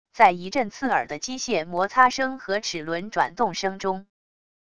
在一阵刺耳的机械摩擦声和齿轮转动声中wav音频